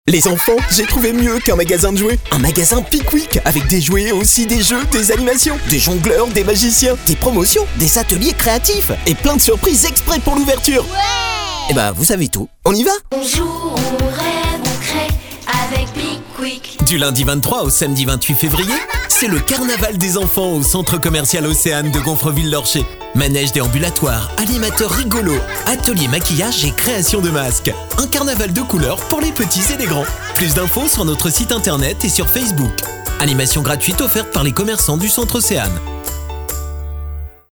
Commercieel, Stoer, Veelzijdig, Warm, Vriendelijk
Commercieel